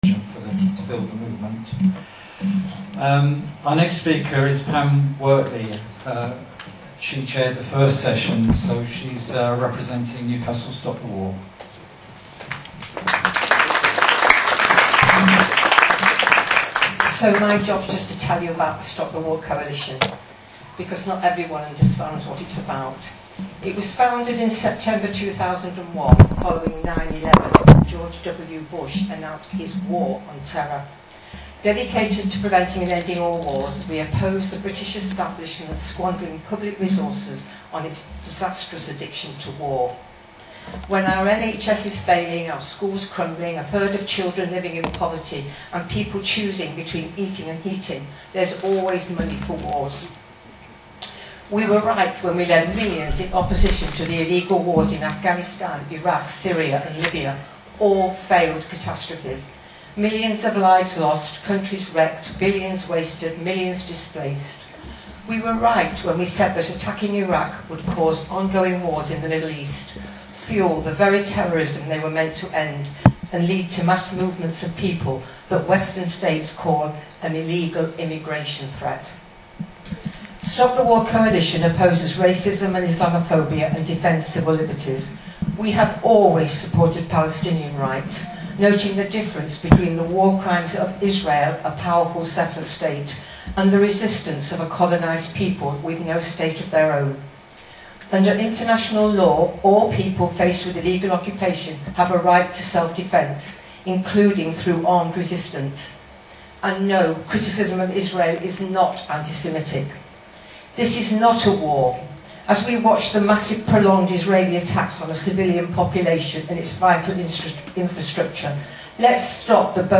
On Sunday March 3 an Anti-war assembly was held in Newcastle spanning two sessions on Palestine, war and the Middle East and building a mass movement for Palestine.
Audio of speeches: